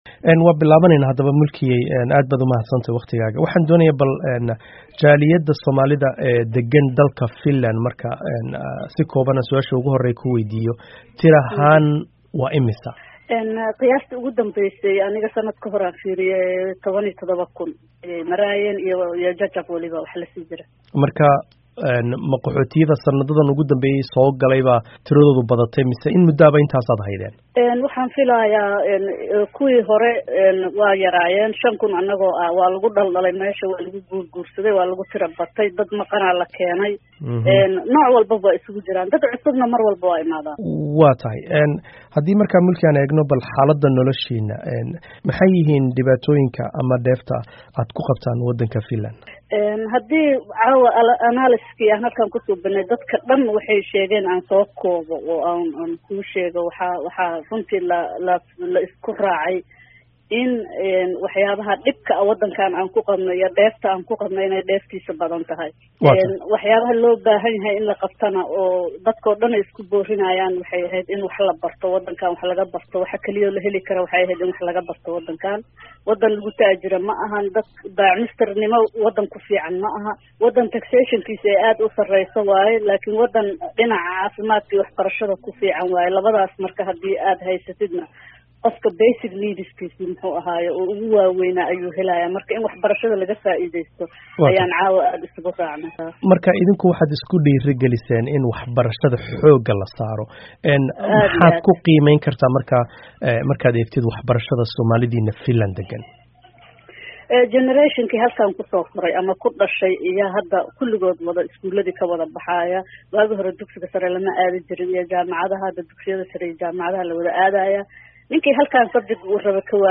Wareysi: Jaaliyadda Soomaalida Finland.